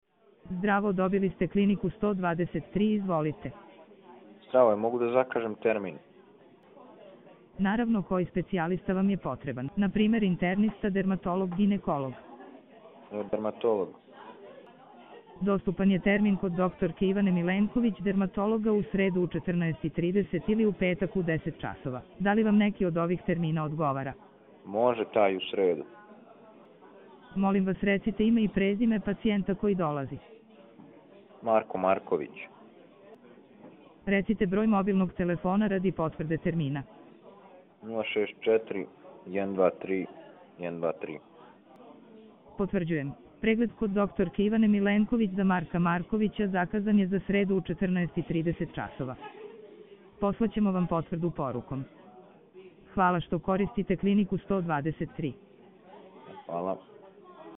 Ovo su demonstracije stvarnih scenarija u kojima AI agent razgovara kao pravi operater.